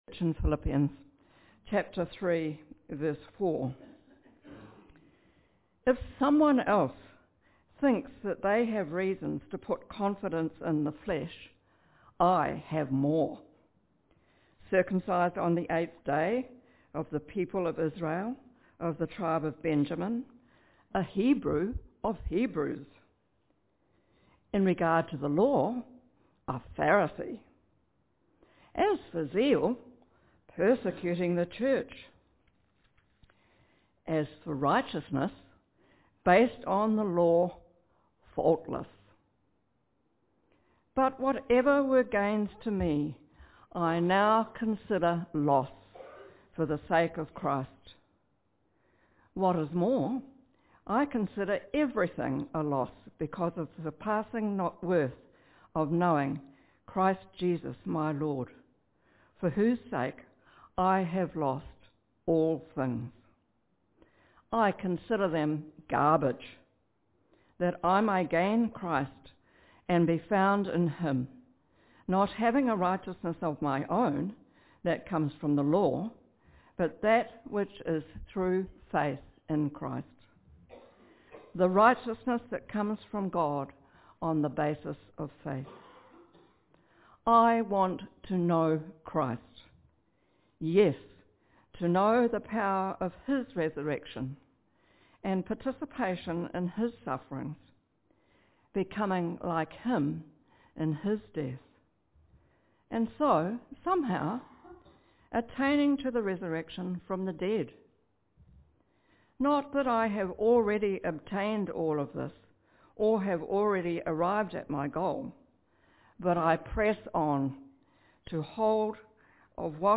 Listen and Watch our sermons - Cambridge Baptist Church